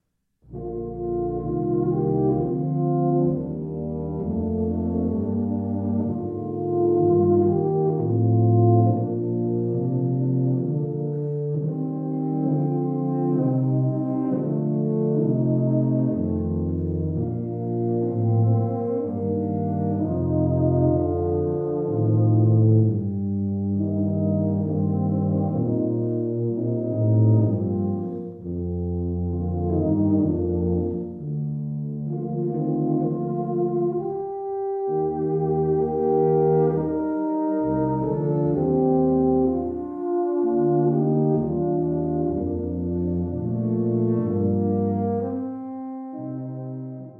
For Tuba Quartet (EETT)